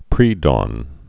(prēdôn)